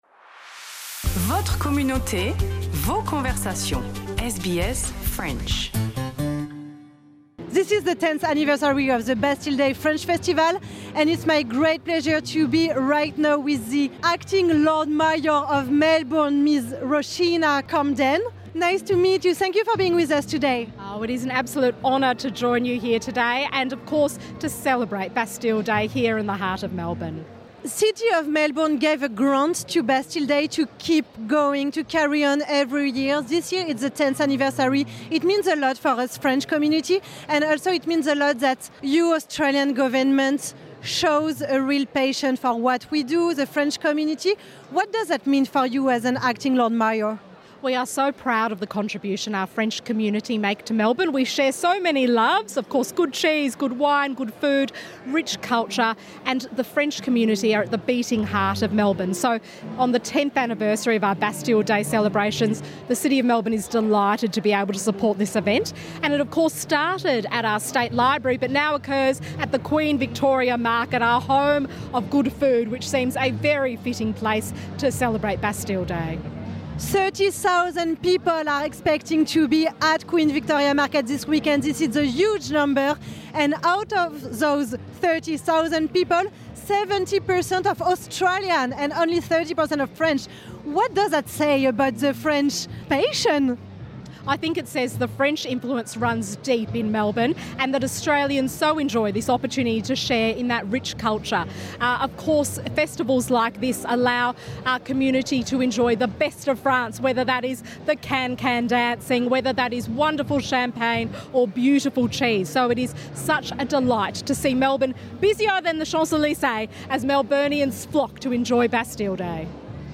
Entretien en anglais avec l’Acting Lord Mayor de Melbourne, Ms Roshena Campbell.